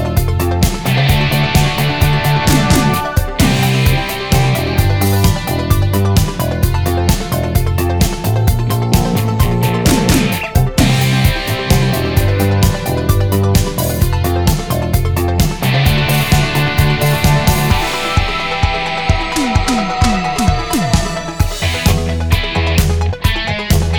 Minus Lead Guitar Pop (1980s) 3:34 Buy £1.50